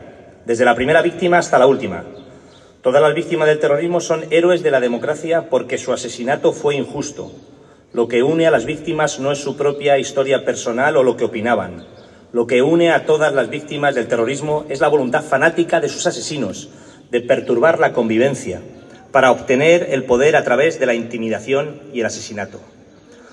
Asimismo, Velázquez ha participado en la lectura de un manifiesto, acordado por unanimidad de todos los grupos con representación municipal, que recoge la voluntad de defender la memoria de Miguel Ángel Blanco, frente al olvido.
Cortes de voz
carlos-velazquez-manifiesto-1.m4a